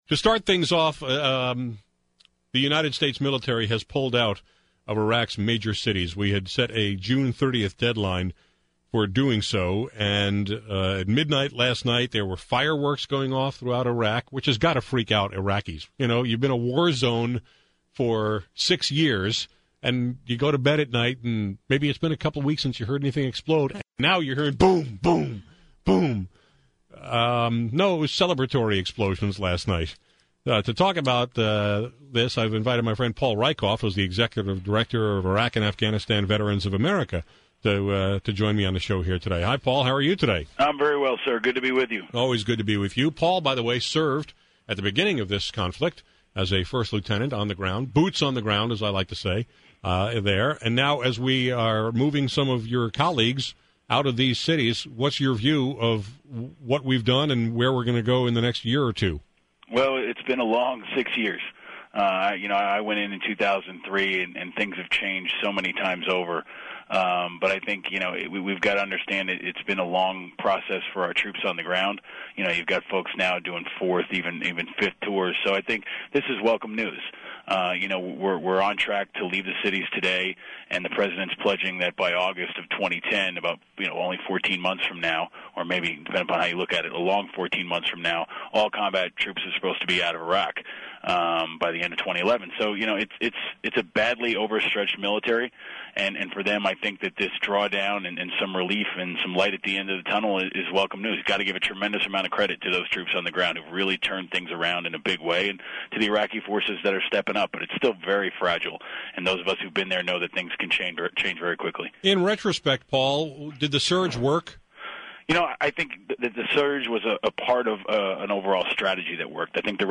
Today on KTRS/St. Louis, I wanted to get some perspective from someone who served in this war on the US military handing over control of Iraq’s major cities to their own security forces today.